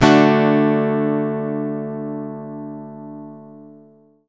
OVATION D-DU.wav